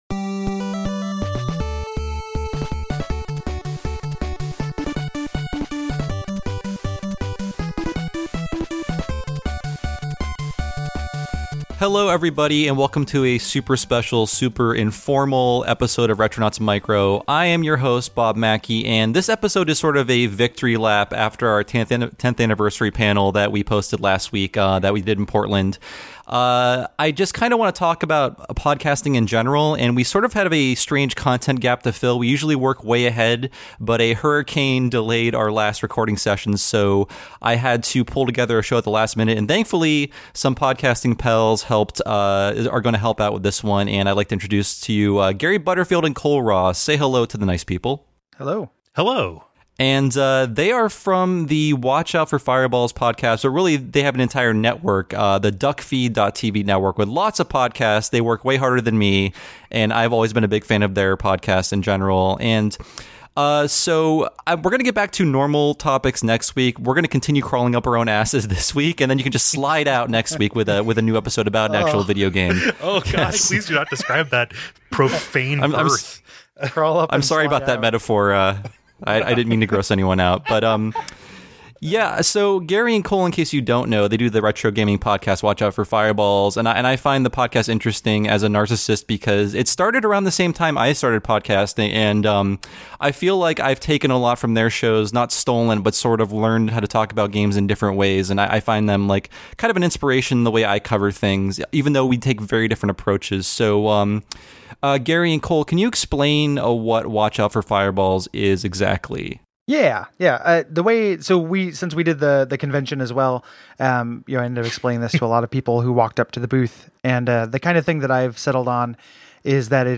a laid-back discussion about the podcasting game